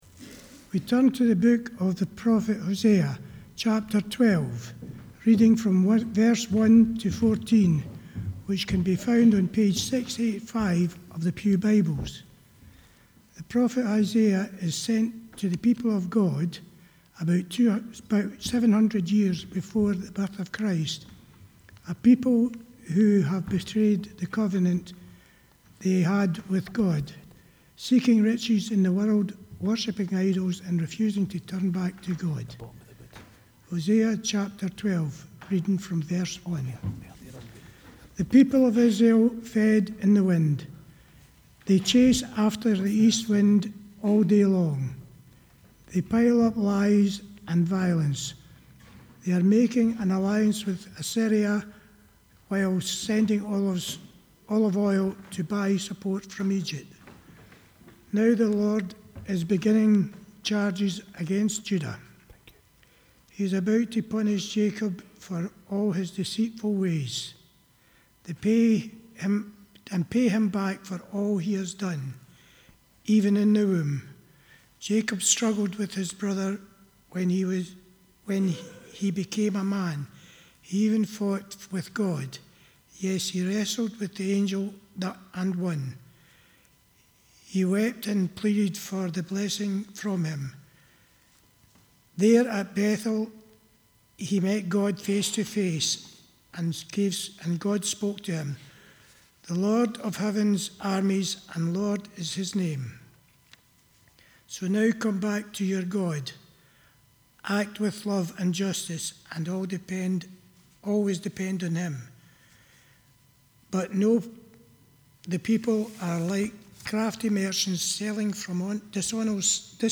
The reading prior to the sermon is Hosea 12: 1-14